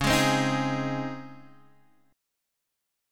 DbM7sus2 chord